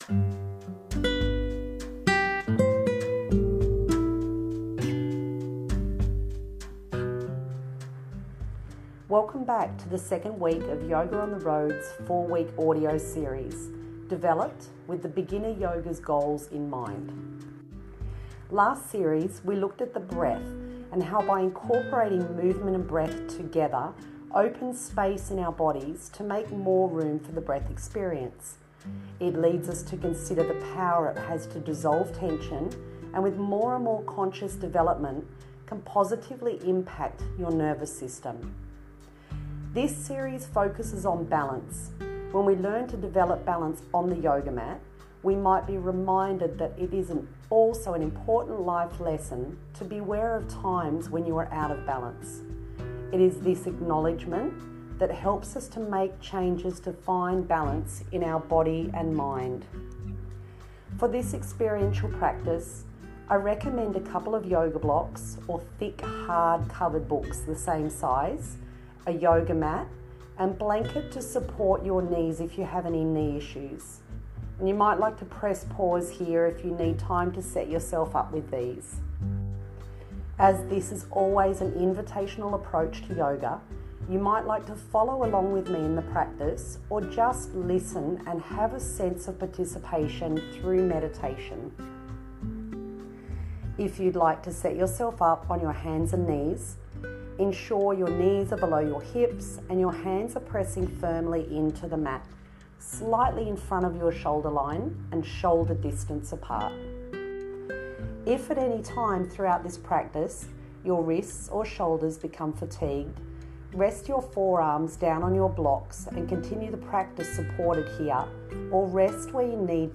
Designed for beginners and advanced beginners, these sessions guide you in cultivating awareness and calm through intentional breathwork. Set personal goals, deepen your connection to your mind and body, and rediscover balance.